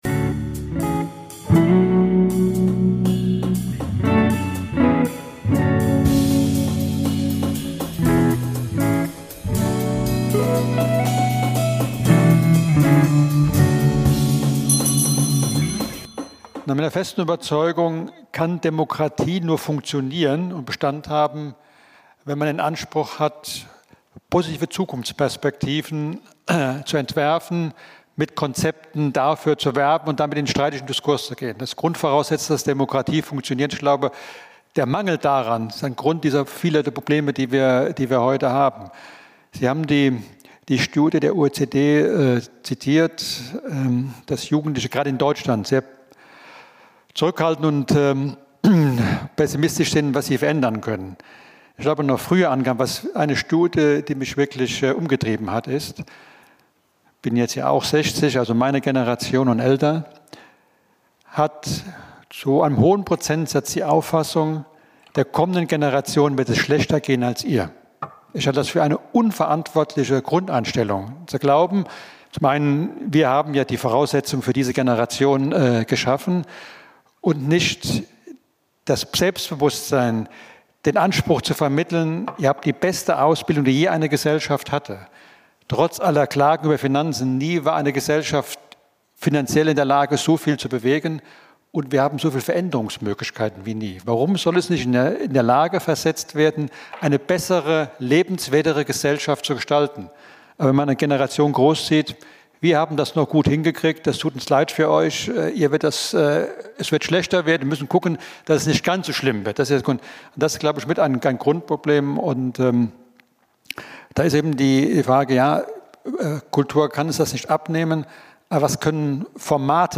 Beschreibung vor 1 Jahr Steckt der öffentliche Diskurs in einer Krise? Und welche Bedeutung hat Kultur für eine offene Gesellschaft? Dem gingen Dr. Carsten Brosda, Hamburger Senator für Kultur und Medien und Präsident des Deutschen Bühnenvereins, Landtagspräsident Hendrik Hering und Staatssekretär Professor Jürgen Hardeck auf die Spur. Die Diskussion ist ein Mitschnitt der Veranstaltung „Hoffnungsmaschine“ aus dem November 2024.